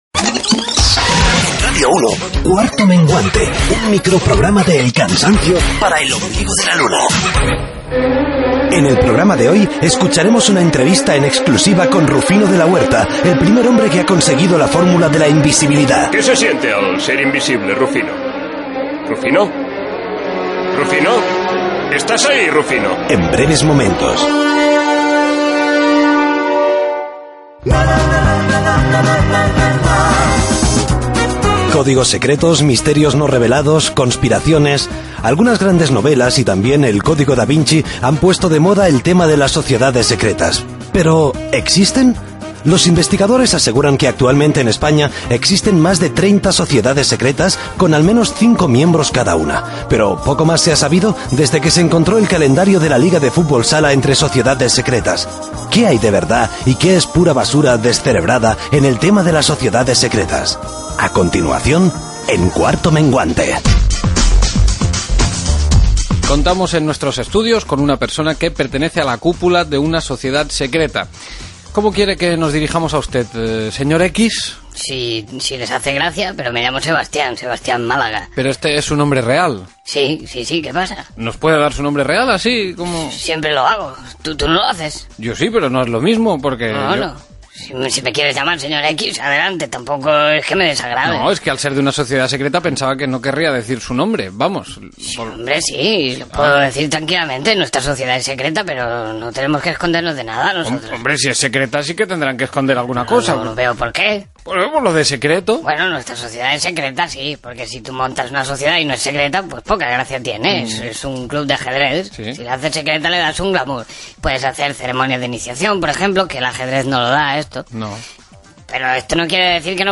Entrevista a un integrant de la societat secreta Los Calvarios, salutació del músic Phil Collins (paròdia), indicatiu de l'espai, "Cultura recomendada", publicitat fictícia Gènere radiofònic Entreteniment